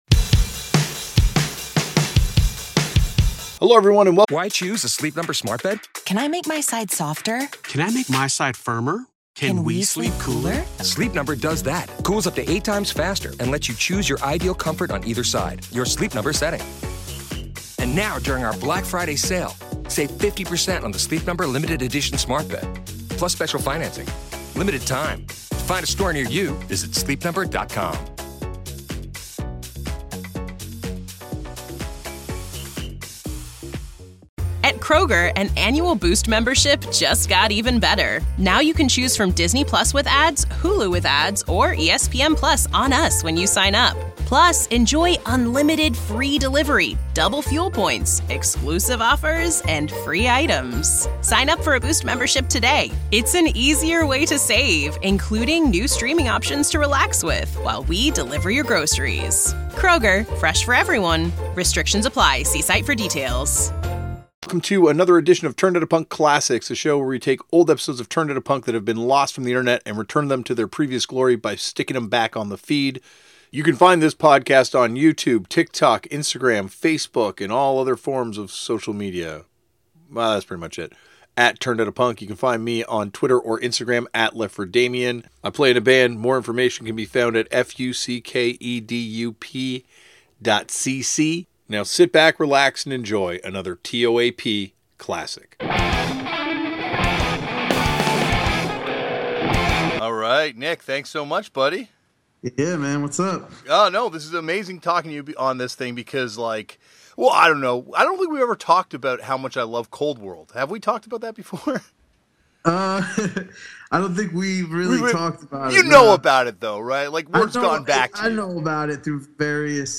Postgame Show